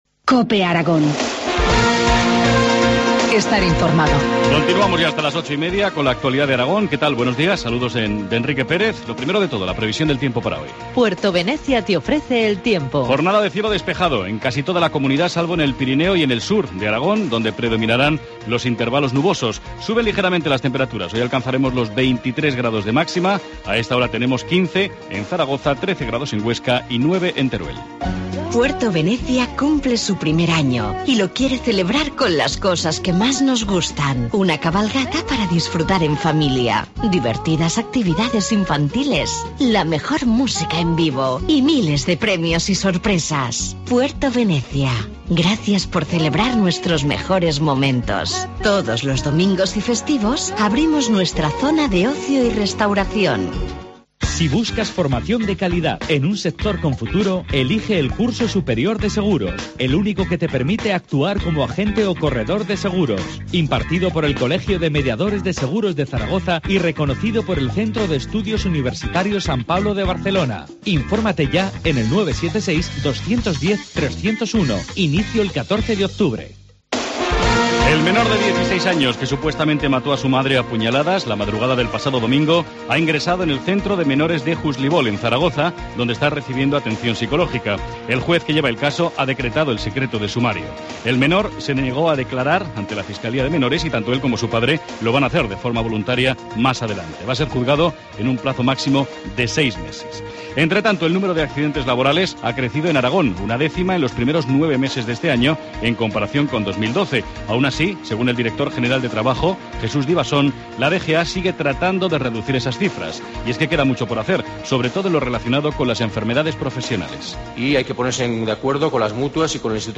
Informativo matinal, martes 8 de octubre, 8.25 horas